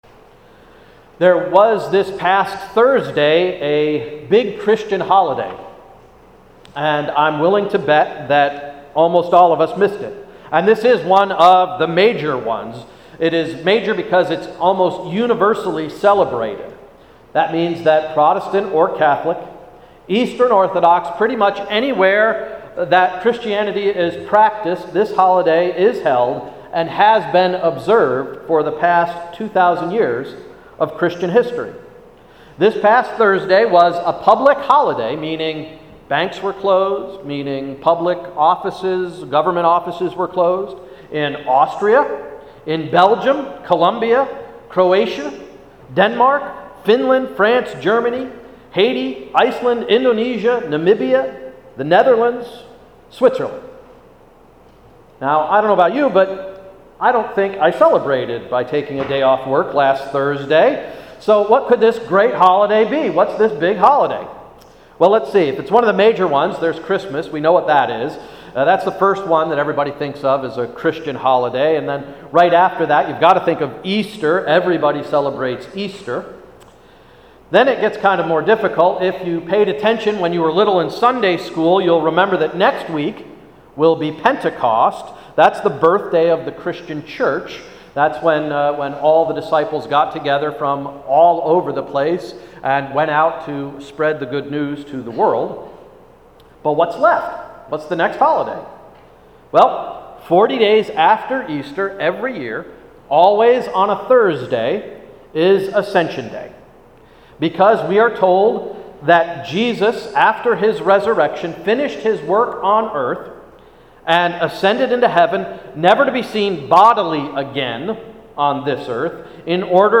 Sermon of June 5, 2011–“Rising Futures”